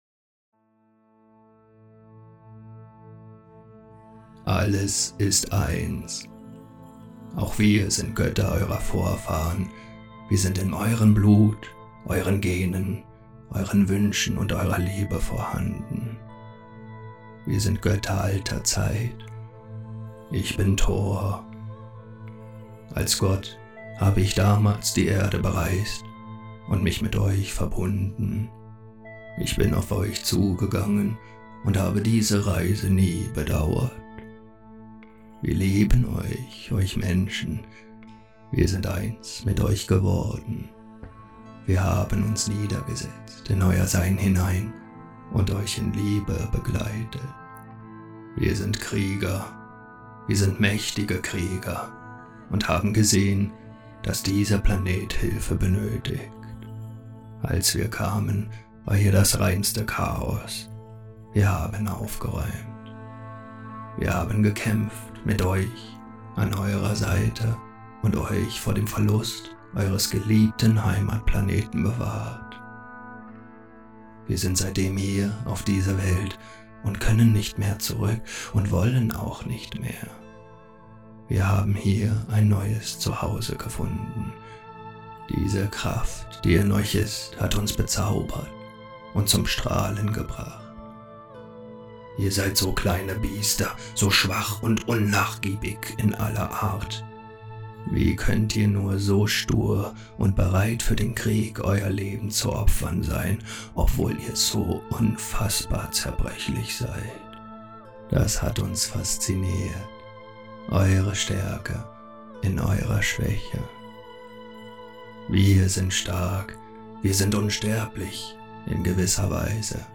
Channeling